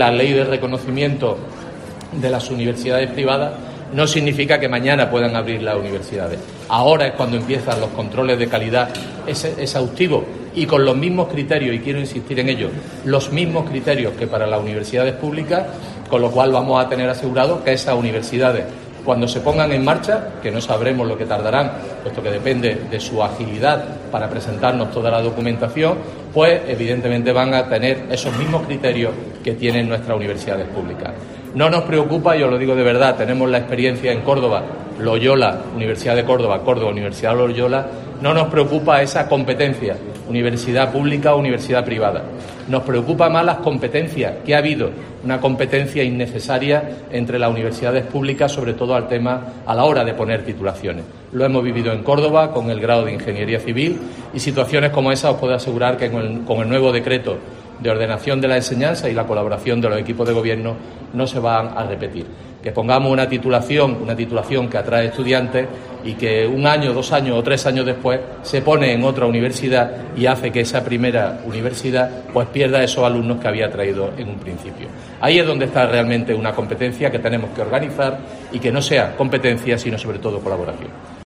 Así lo ha asegurado Gómez Villamandos, en declaraciones a los periodistas previas al citado acto y después de que el rector de la UCO, Manuel Torralbo, recordara al consejero que lo que "preocupa" a los rectores de las universidades públicas andaluzas es que se autoricen, sin el mismo estándar de calidad que se exige a las públicas, a dos nuevas universidades privadas en la comunidad, la Europea de Andalucía y la Alfonso X Mare Nostrum, ambas con implantación prevista en Málaga capital.